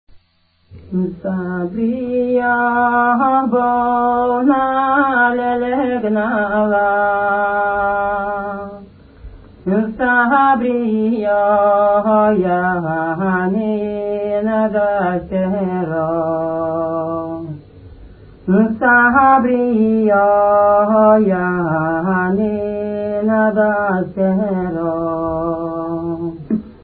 музикална класификация Песен
размер Безмензурна
фактура Едногласна
начин на изпълнение Солово изпълнение на песен
фолклорна област Пирински край (Югозападна Б-я)
място на записа Ракитово
начин на записване Магнетофонна лента